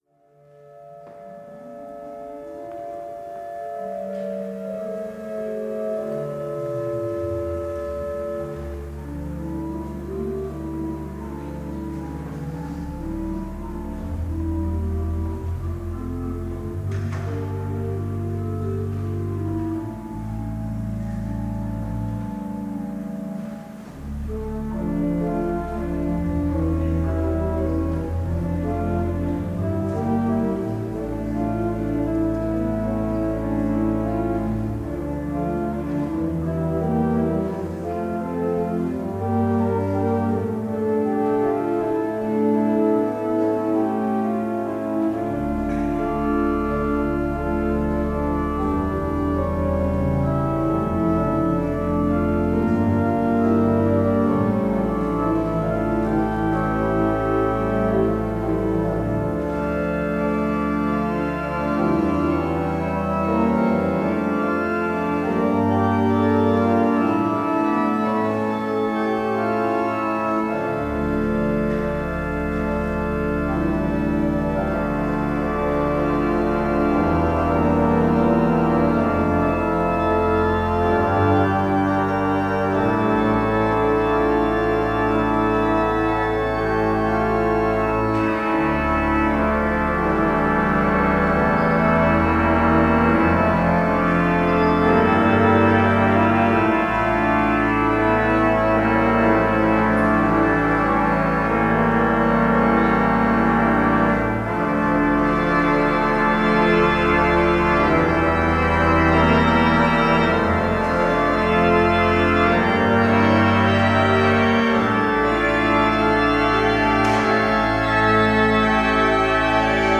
Lezingen
Eucharistieviering beluisteren vanuit de Jozefkerk te Wassenaar (MP3)